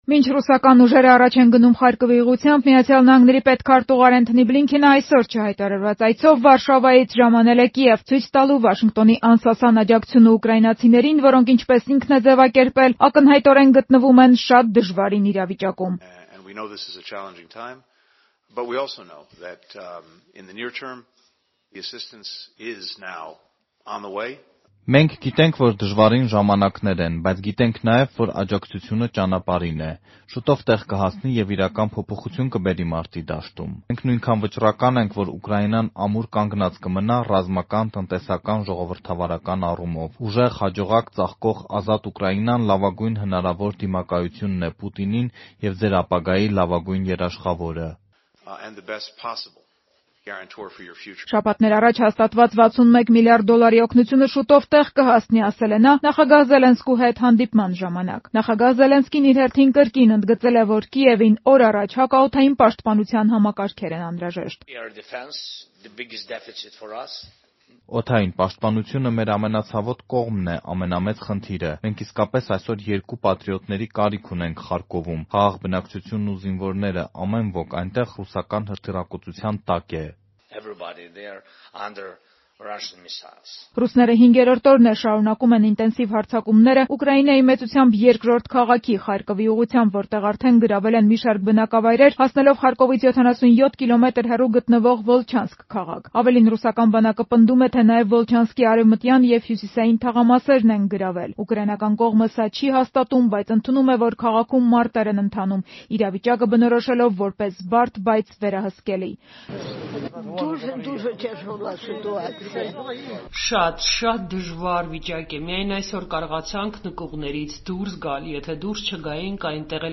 Մանրամասները՝ «Ազատության» ռեպորտաժում.